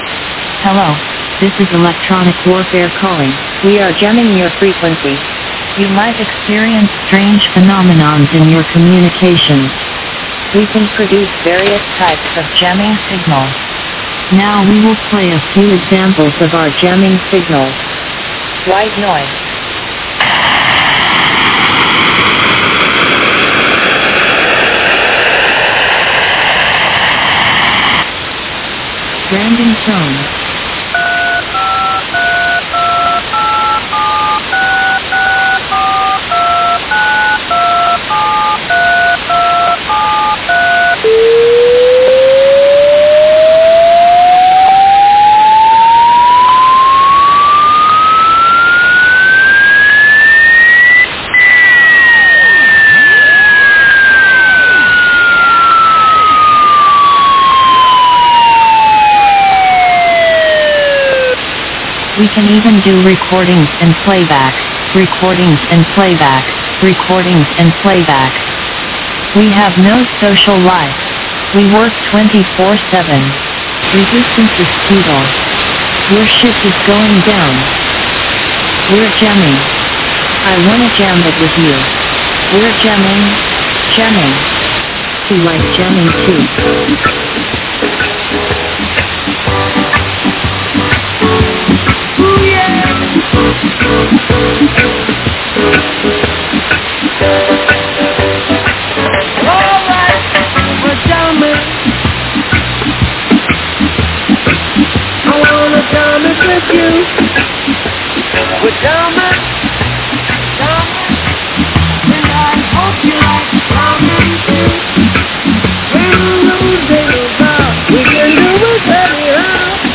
Electronic Warfare Jamming pirate (see N&O #336)
EWjamming.mp3